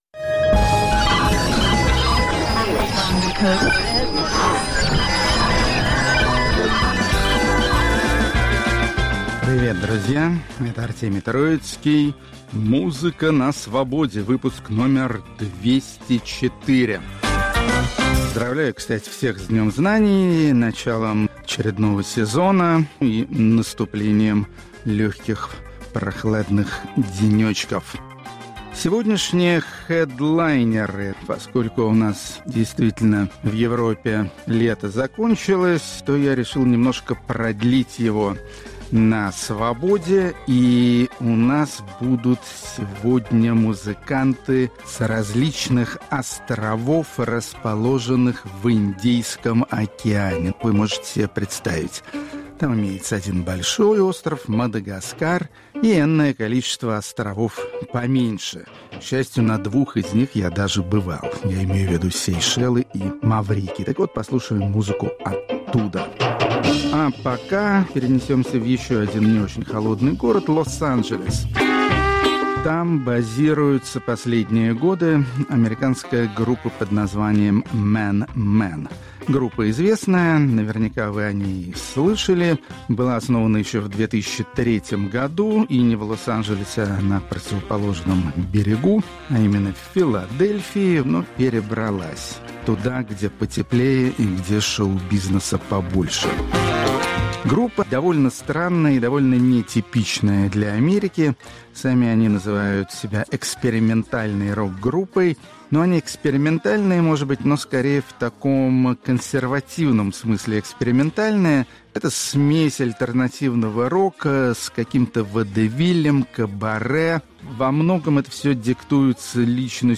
Исполнители с островов Индийского океана, способные удивить весь мир культурным разнообразием, но пока этого не сделавшие. Рок-критик Артемий Троицкий популяризирует береговую музыку тропических и экваториальных широт.